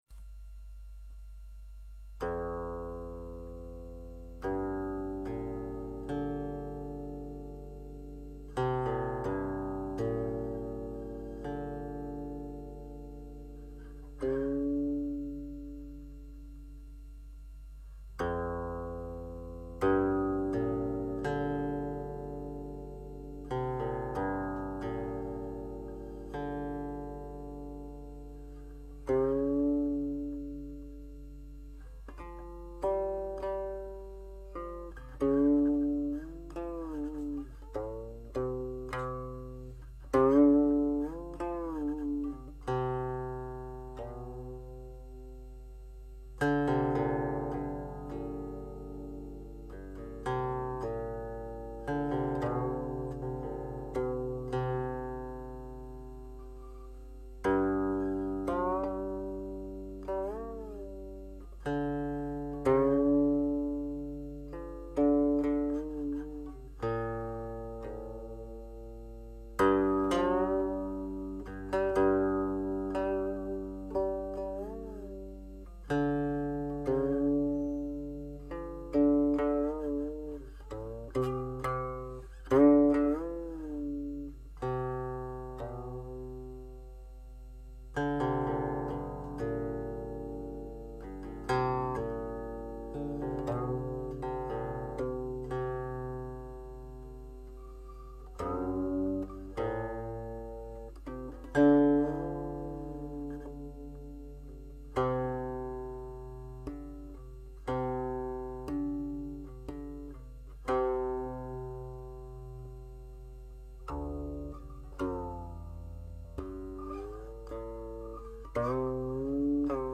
佛音 诵经 佛教音乐 返回列表 上一篇： 观照心间 下一篇： 无尘 相关文章 消业障六道金刚咒(男声